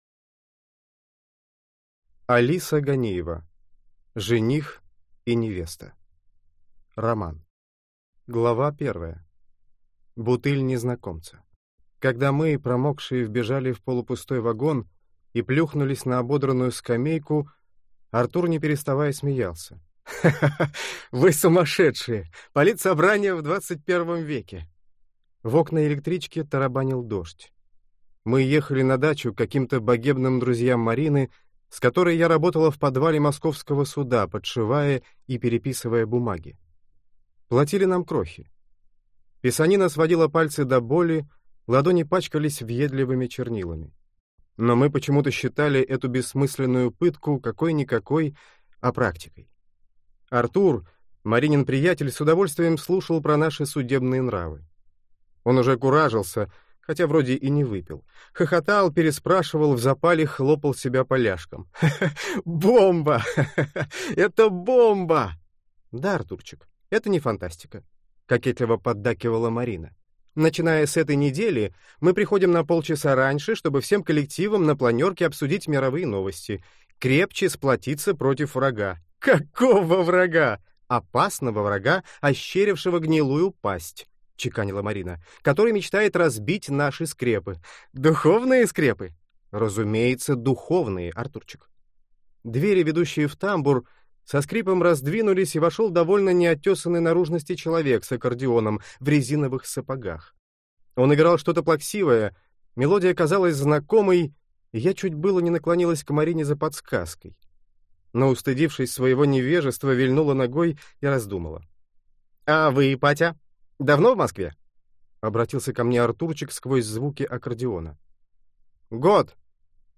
Аудиокнига Жених и невеста | Библиотека аудиокниг